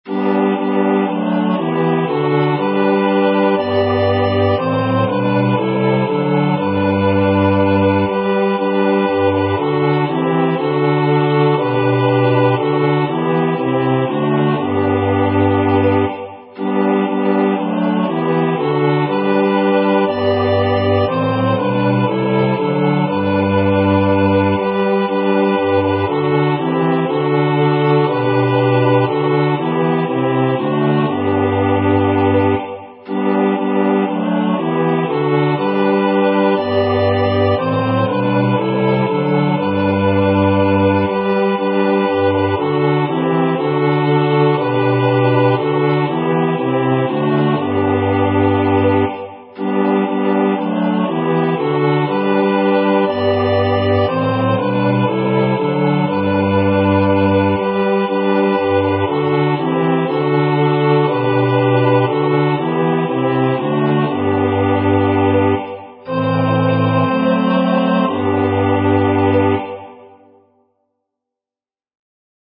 SA TB Version:       + accomp.:
Number of voices: 4vv   Voicing: SATB
Genre: SacredMotet
Instruments: Organ